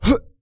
XS被击倒02.wav
人声采集素材